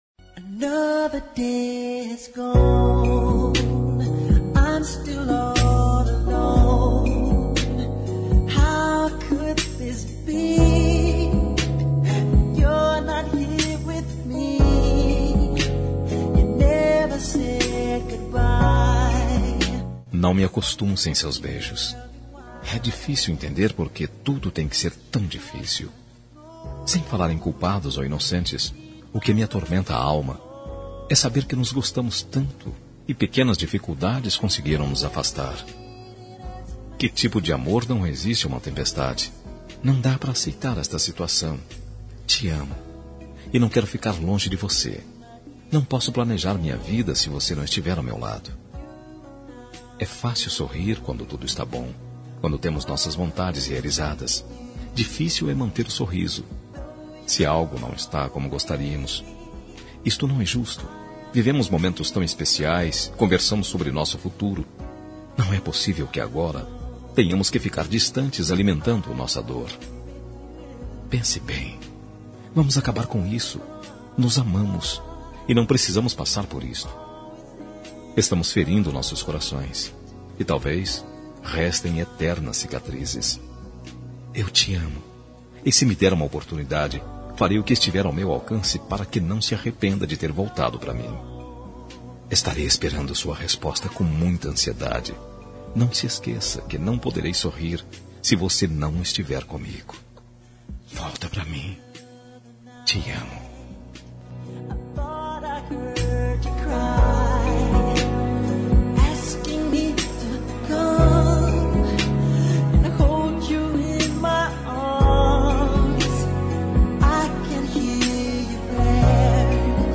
Telemensagem de Reconciliação Romântica – Voz Masculina – Cód: 919